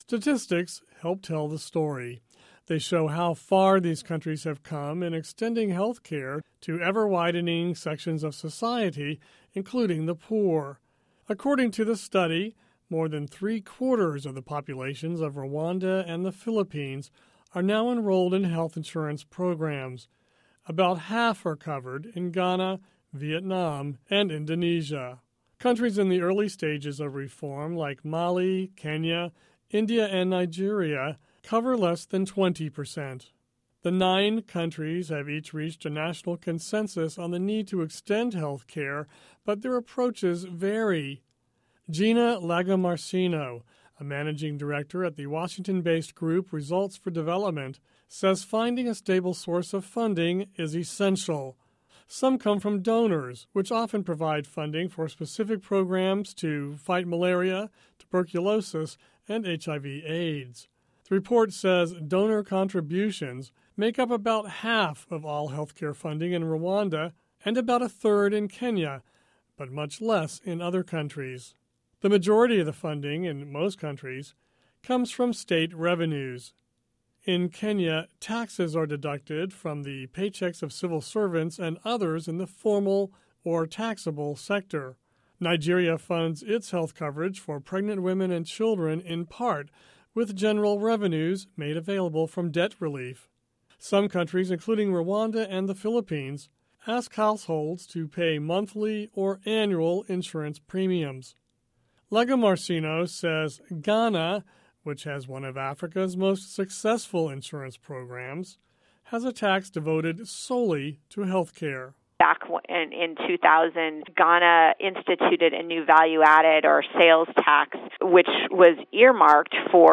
Listen to report on health insurance in Africa